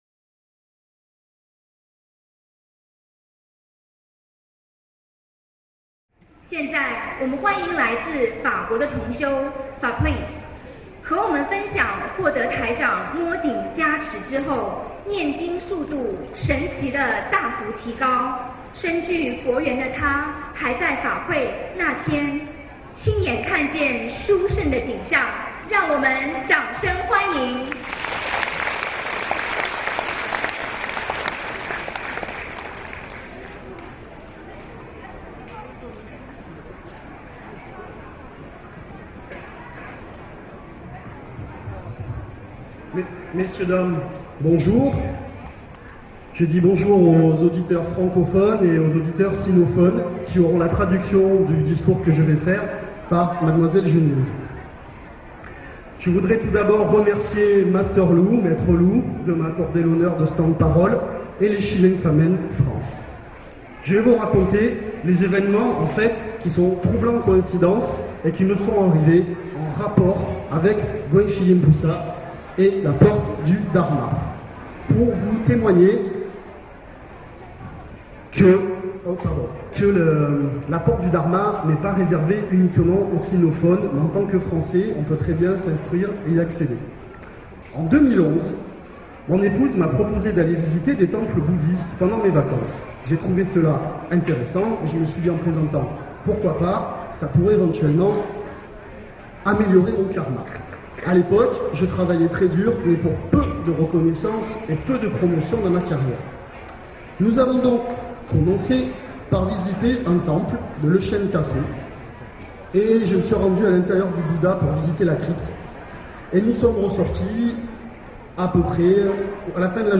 音频：法国西人分享通过学佛事业顺利，看见千手观音201309法国巴黎